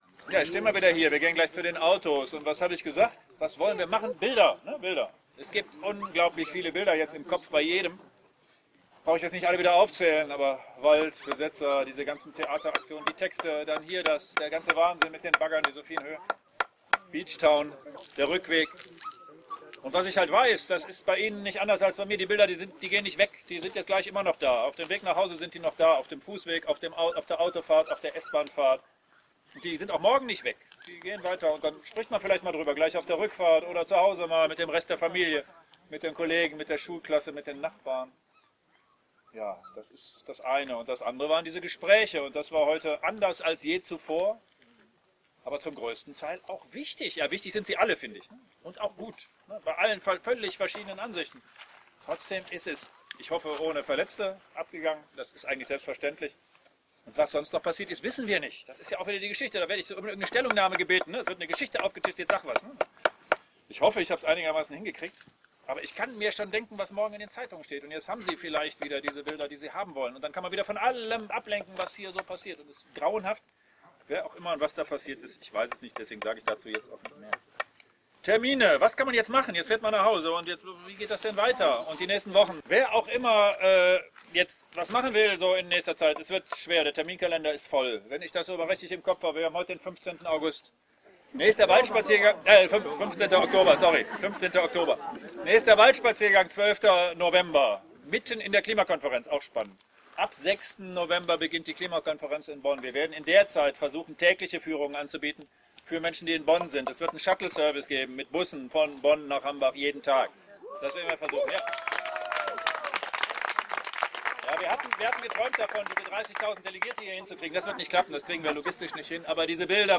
42. Waldführung im Hambacher Forst (Audio 20/20)